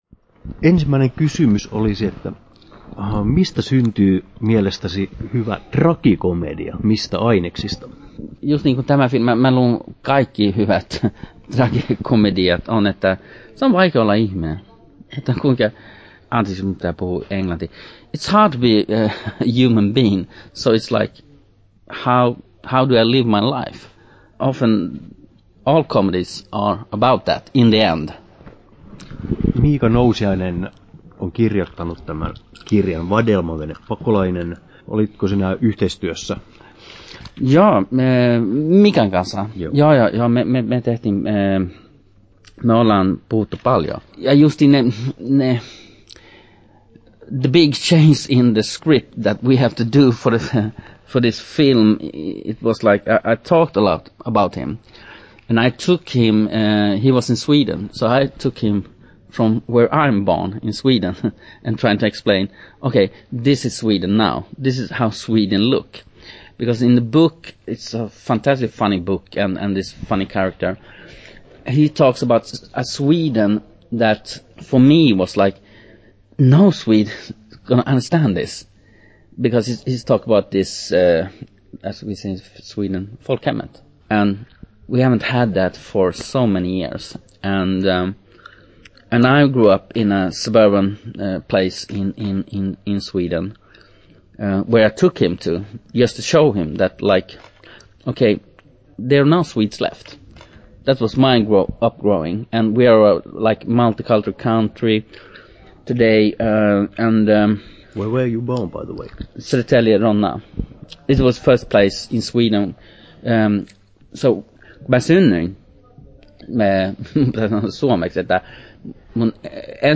Haastattelut